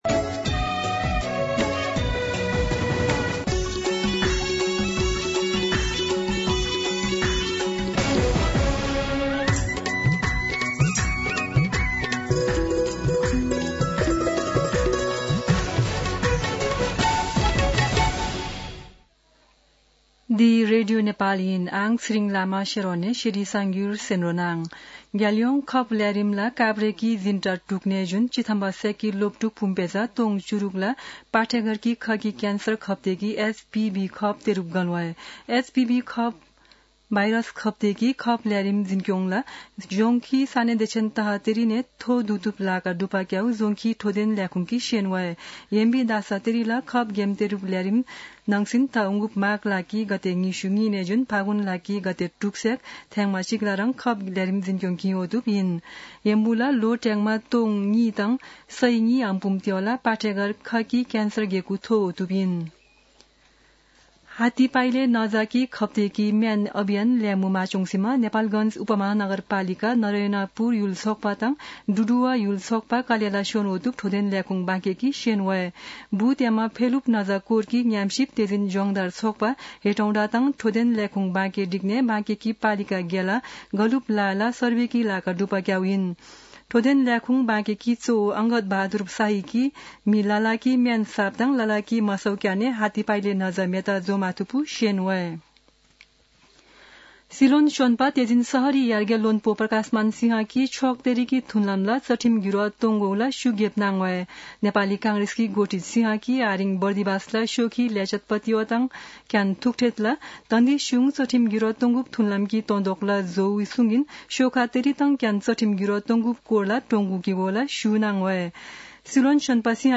An online outlet of Nepal's national radio broadcaster
शेर्पा भाषाको समाचार : २१ पुष , २०८१